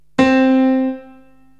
PIANO 0008.wav